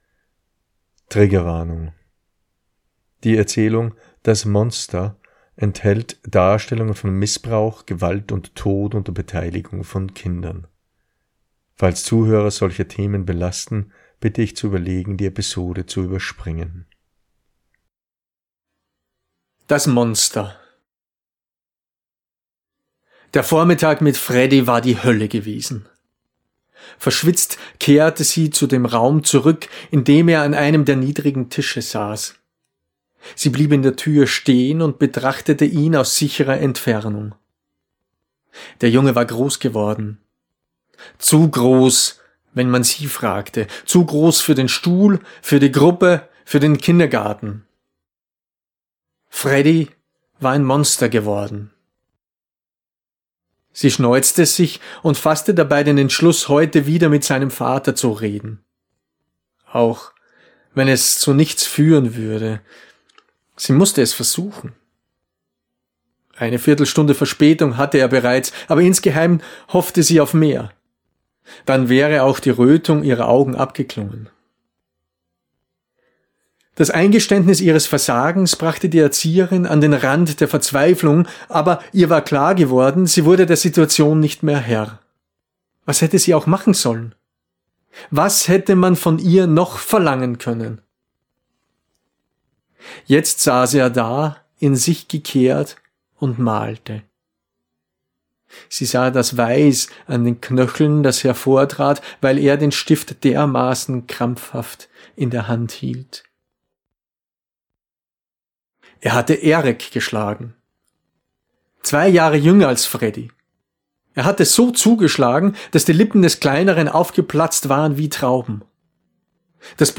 Erzählung